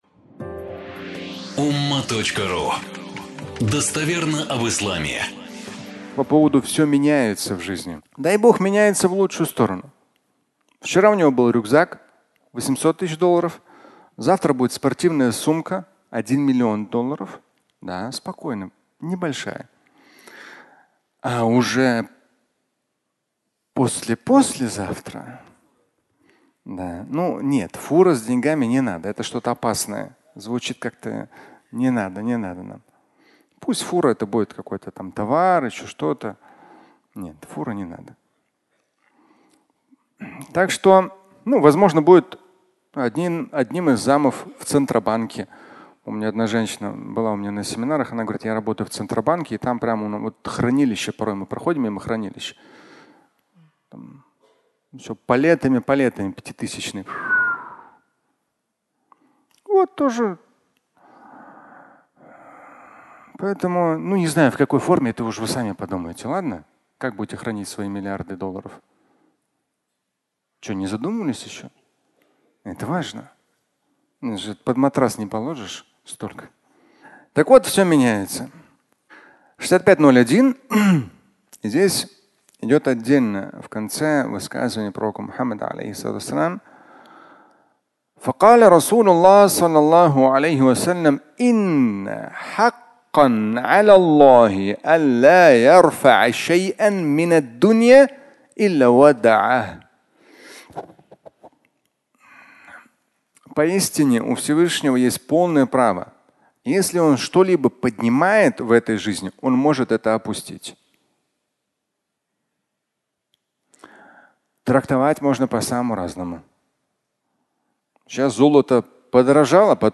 Все меняется (аудиолекция)